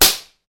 Slap1.wav